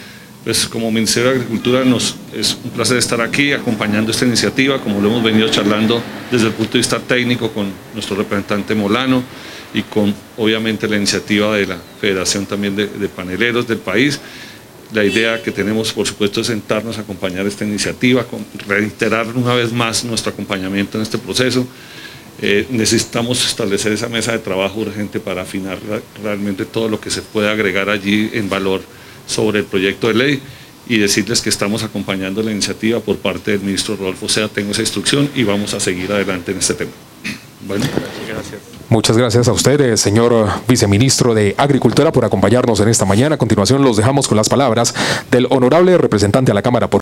Declaración del viceministro de Desarrollo Rural, Omar Franco.